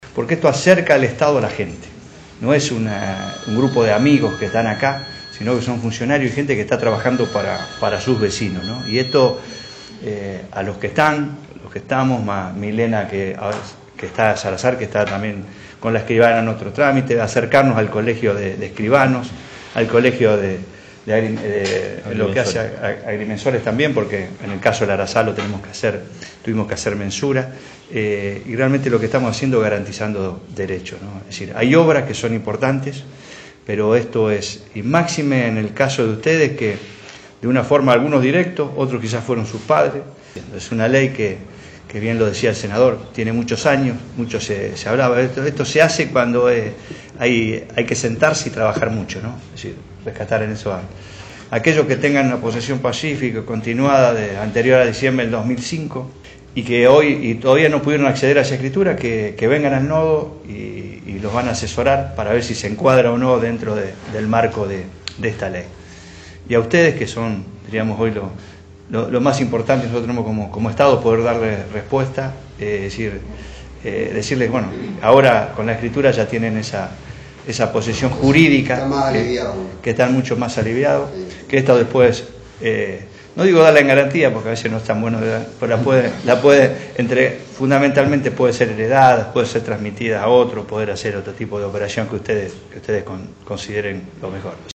Audio del Ministro Jorge Alvarez.